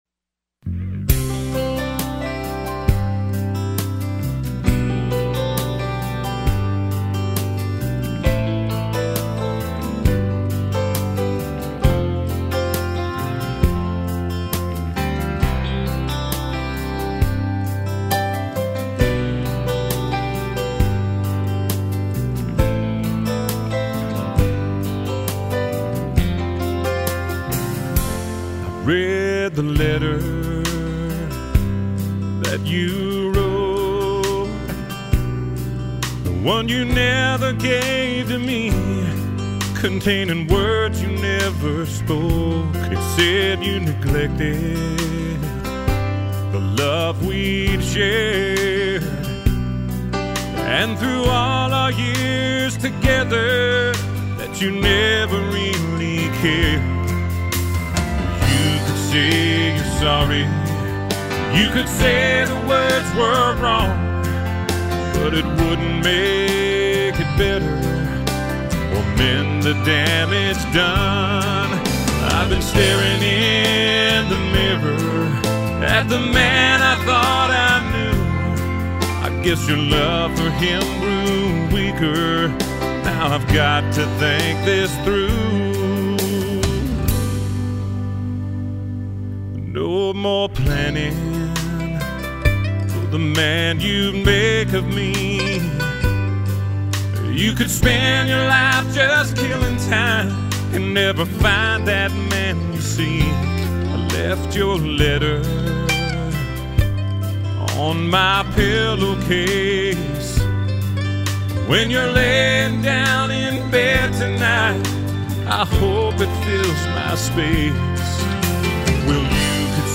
Check out the demo recording of our ‘Dear John’-type song The Letter.
recording studio